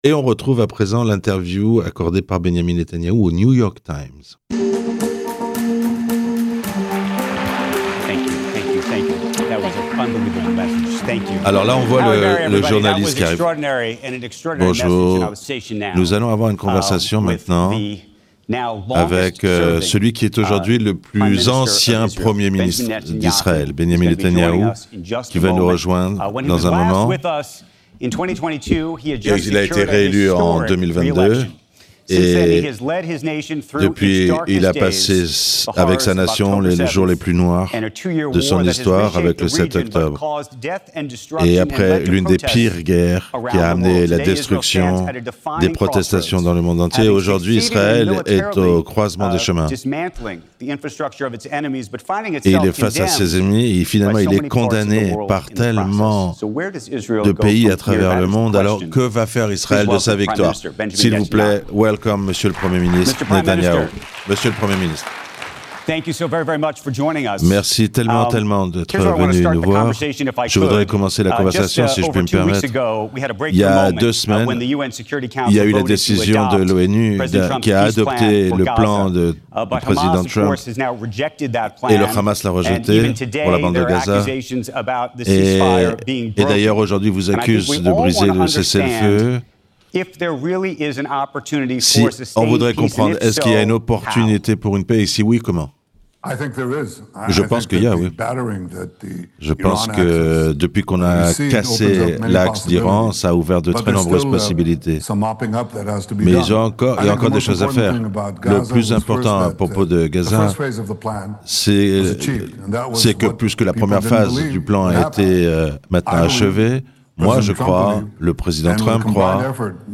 Ecoutez l’interview de Netanyahou par le New York Times traduite en français